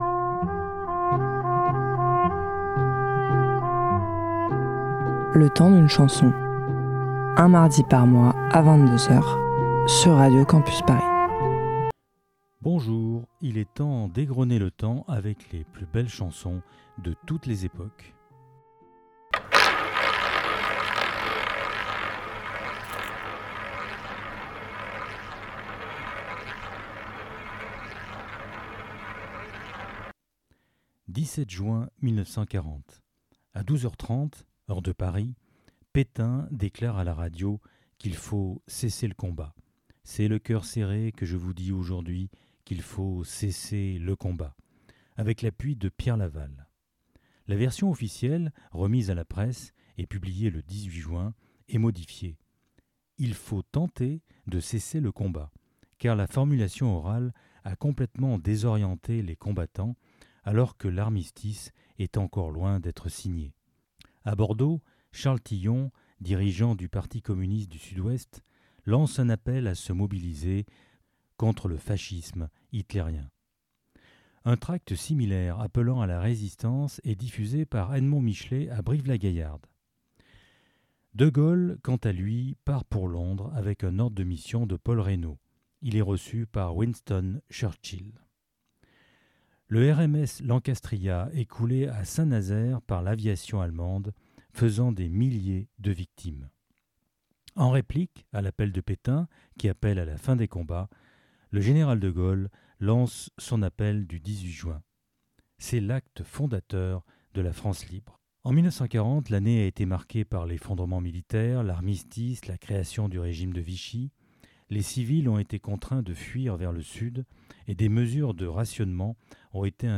Type Musicale Pop & Rock